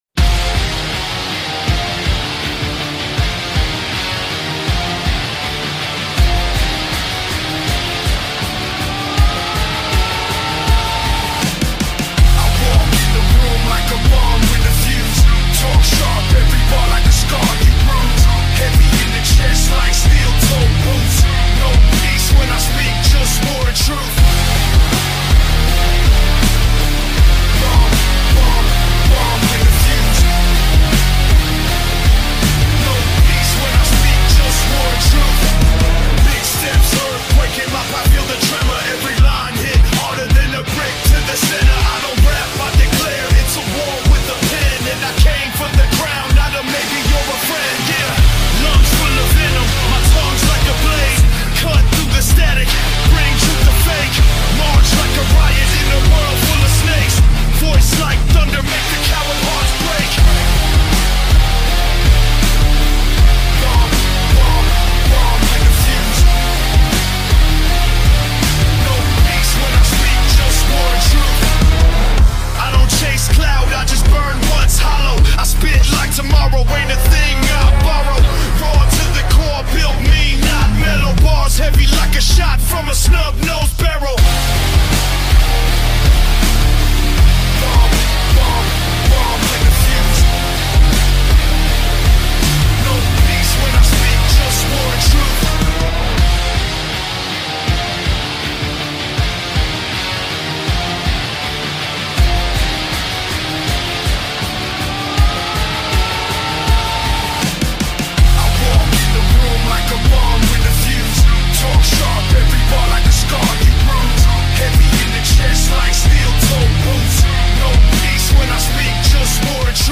Theme Remix